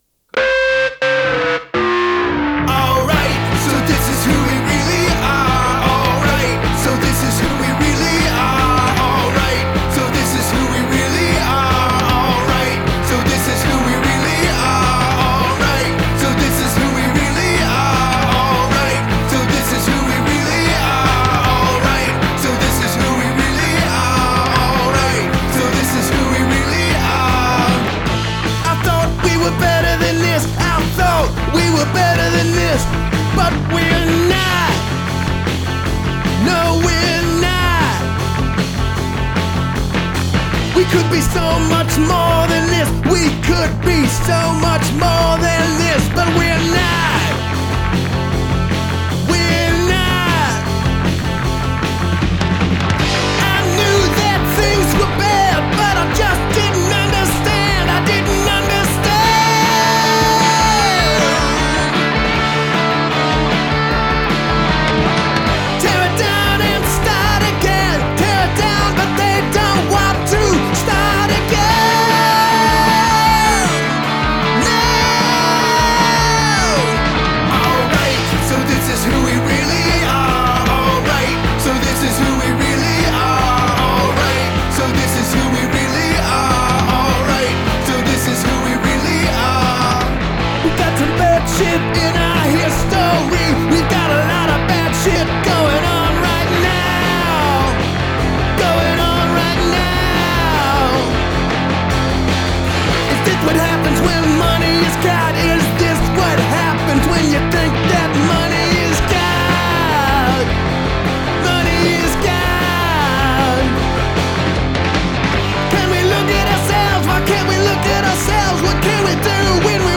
It’s punk rock. It’s loud.